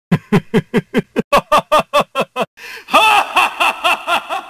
iori yagami laugh
iori yagami laugh.mp3